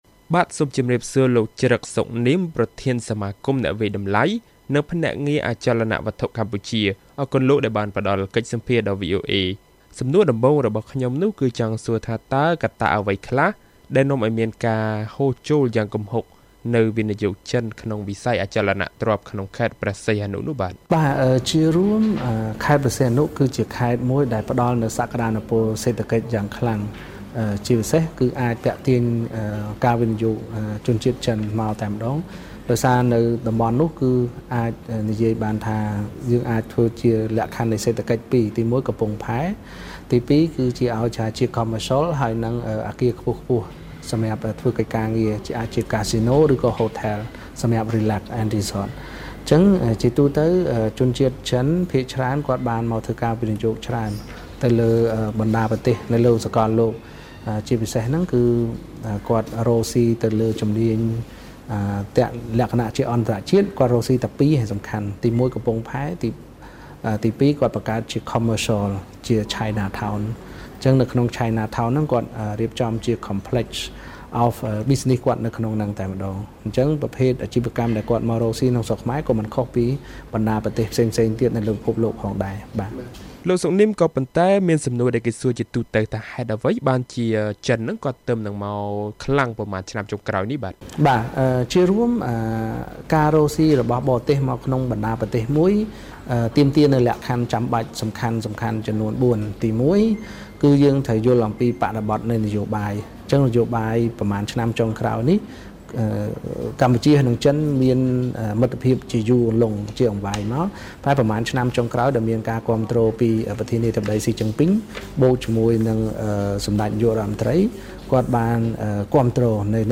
បទសម្ភាសន៍ VOA៖ ការវិនិយោគរបស់ចិនក្នុងវិស័យអចលនទ្រព្យតំបន់ឆ្នេរ